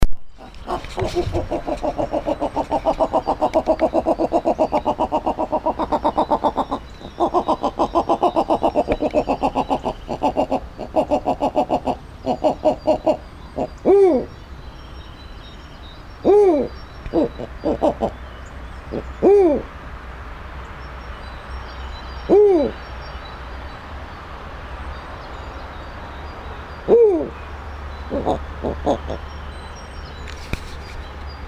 Snowy Owl
hoots can be heard more than 3 km away.
snowy.mp3